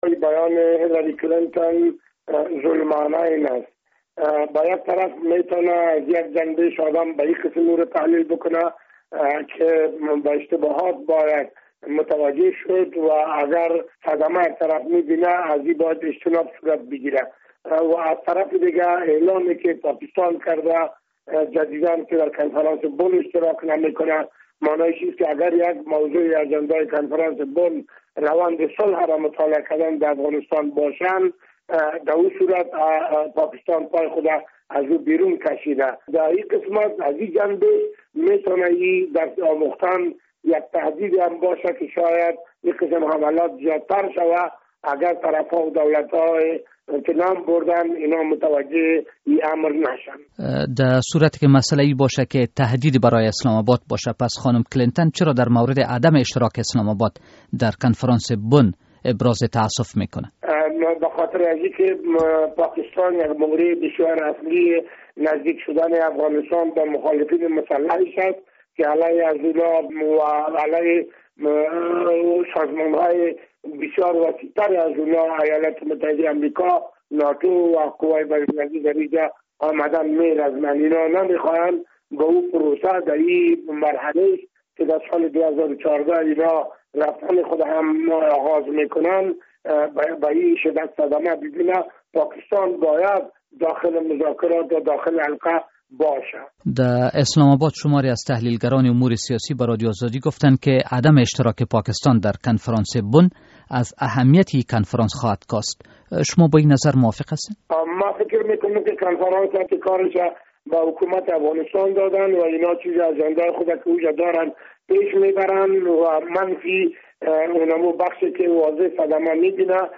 مصاحبه در مورد اظهارات اخیر وزیر خارجهء امریکا